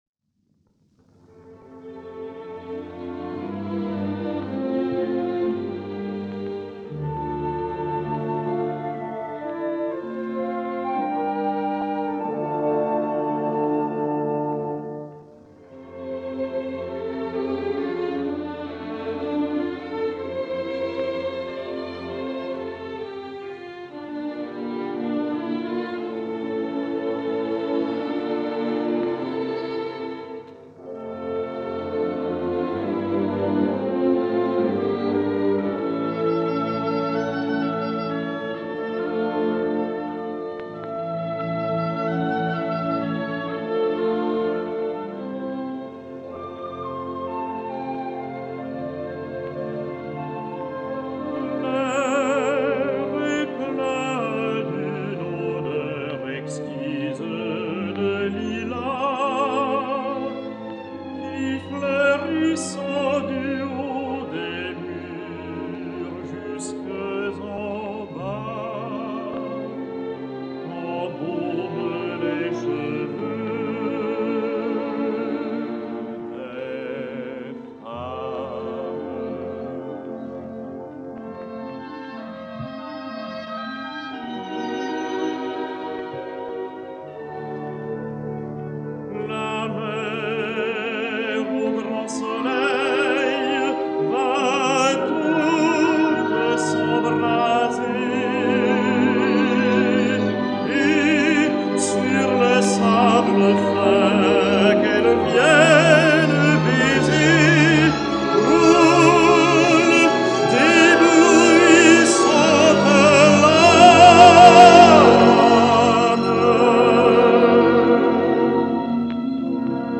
Bariton
ORTF Radio broadcast
As with most all of these French radio sessions, exact dates of recording are almost impossib